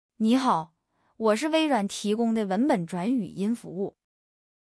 Edge-TTS是微软提供的在线文本转自然语音，支持多种语言和声音，转换速度快，语音自然无机械感。
同一段文字分别使用这几种声音转成语音文件对比：
liaoning-Xiaobei.wav